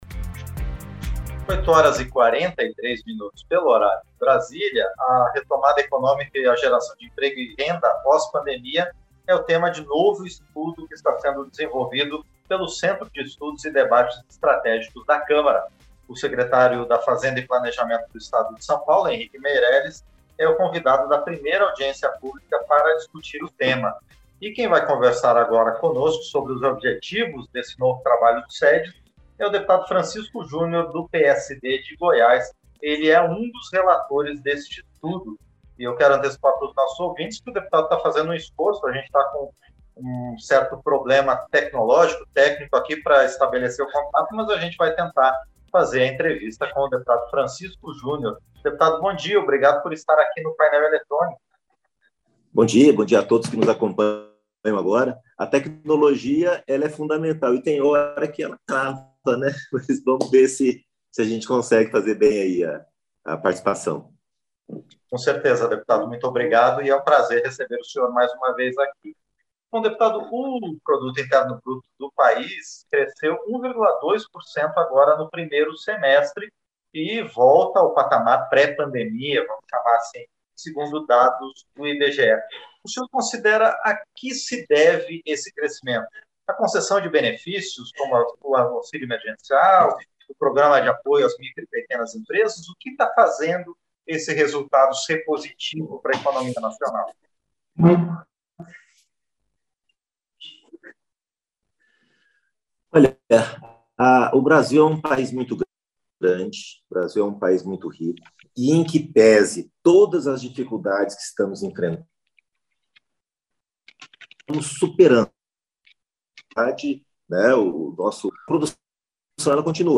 Entrevista - Dep. Francisco Jr (PSD-GO)